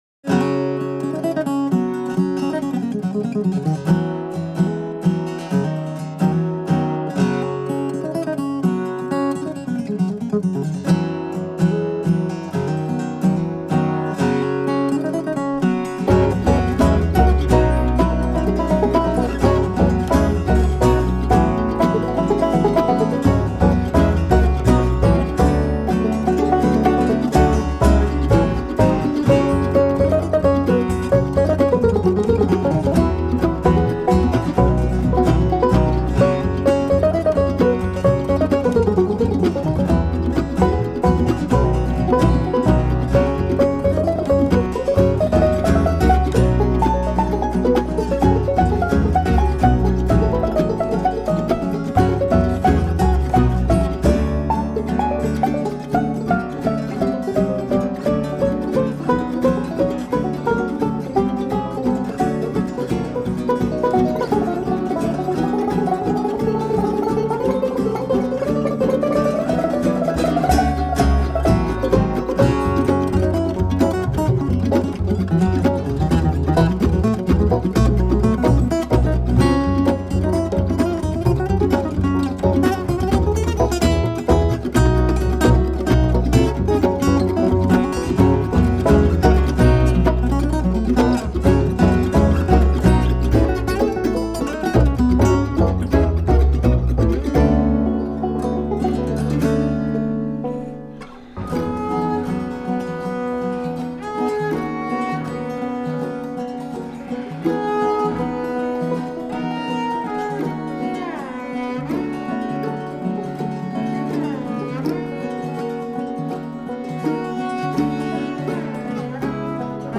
mandolin
banjo
guitar and piano
double bass